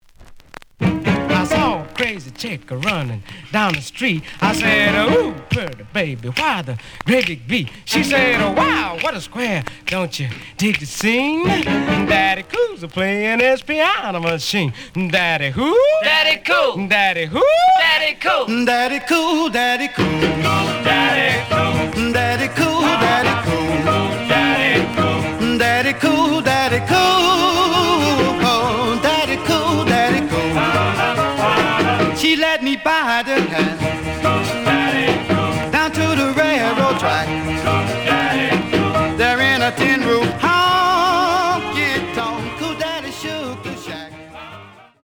The audio sample is recorded from the actual item.
●Genre: Rhythm And Blues / Rock 'n' Roll
Some click noise on both sides due to scratches.)